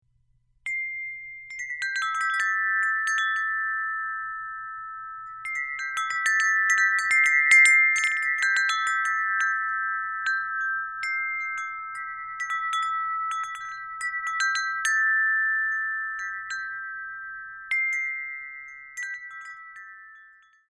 Celebrating the rituals of spring, this wind chime is tuned to the notes of the opening theme of Igor Stravinsky's Rite of Spring. 6 Silver tubes Length: 23.5" sound sample To see more Woodstock windchimes: please click here.